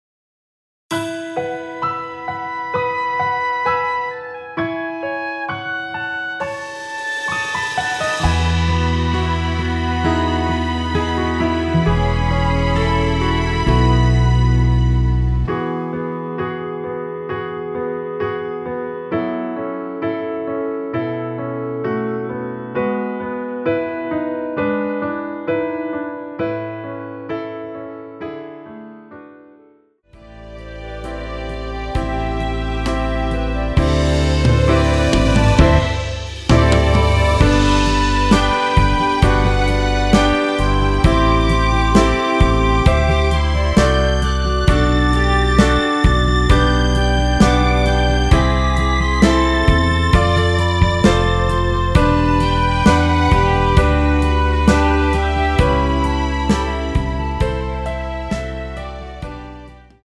원키에서(+6)올린 멜로디 포함된 MR입니다.(미리듣기 확인)
멜로디 MR이란
멜로디 MR이라고 합니다.
앞부분30초, 뒷부분30초씩 편집해서 올려 드리고 있습니다.